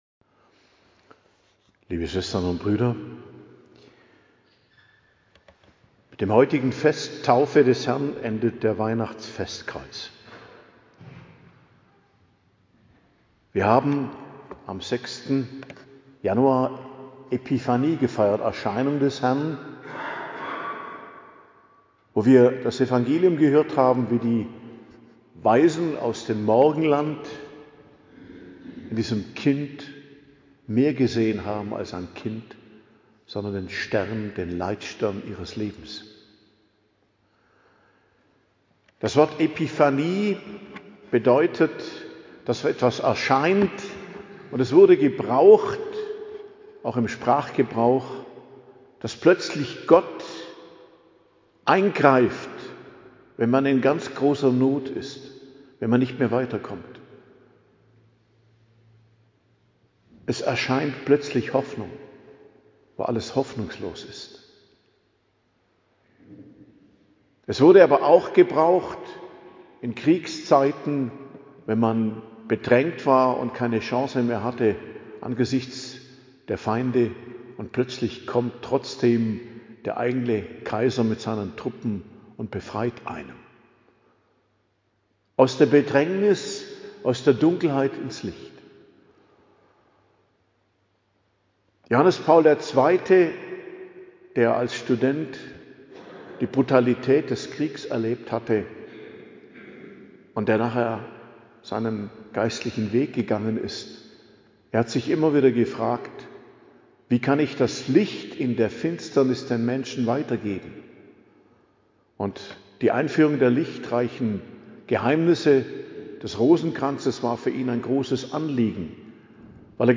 Predigt zum Fest Taufe des Herrn, Sonntag, 11.01.2026